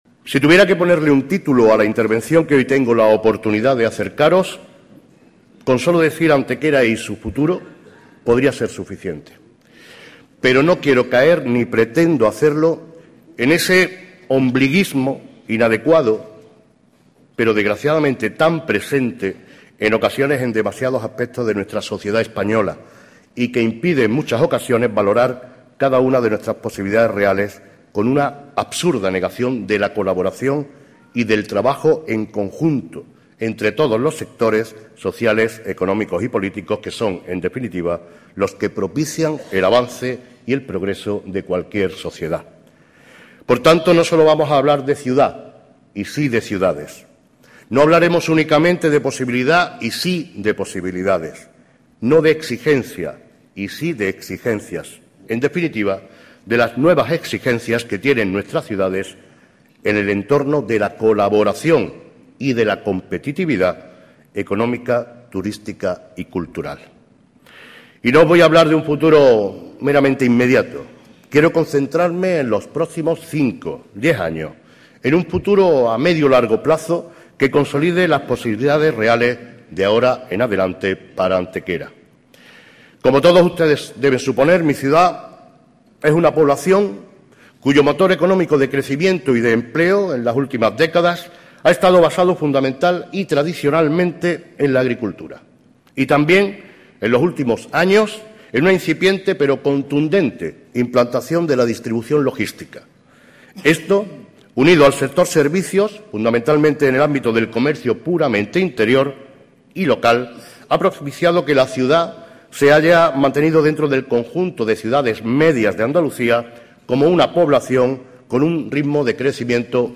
Fotografías alusivas al desarrollo en la mañana de hoy de una nueva edición del Fórum Europa-Tribuna Andalucía que ha estado protagonizado en el Hotel NH de Málaga por el alcalde de Antequera, Manolo Barón, quien ha desarrollado una conferencia sobre "Nuevas Exigencias para nuestras Ciudades en el Entorno de la Colaboración y la Competitividad Económica, Turística y Cultural".
Cortes de voz